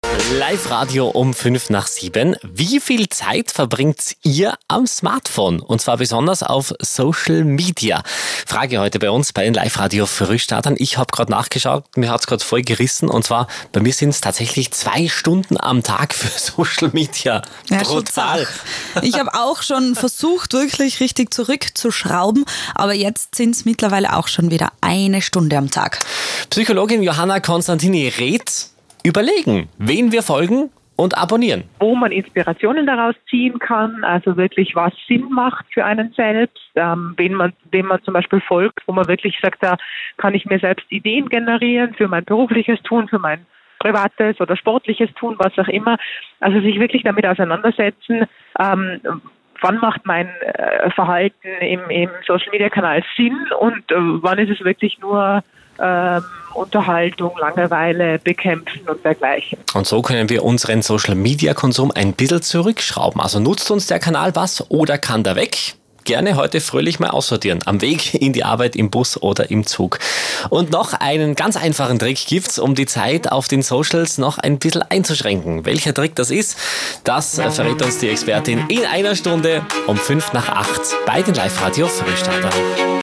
Social Media als Allzeitbegleiter – im Life Radio Interview